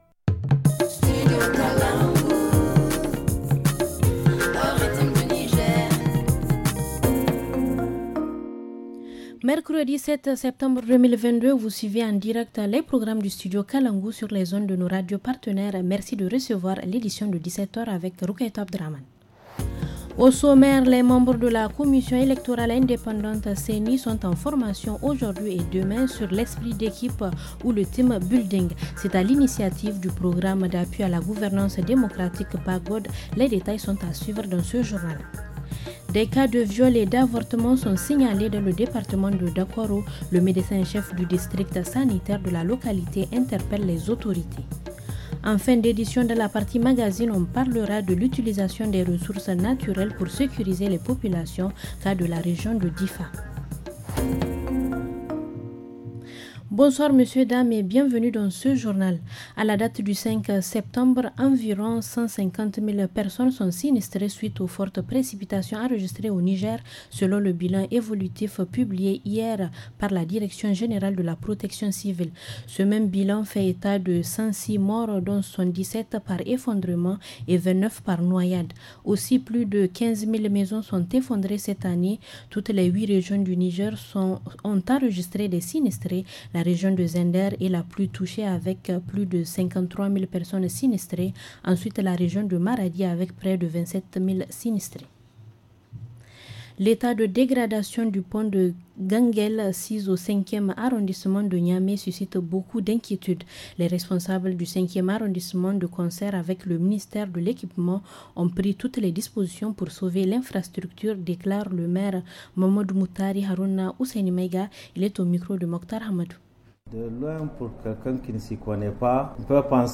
Le journal du 7 septembre 2022 - Studio Kalangou - Au rythme du Niger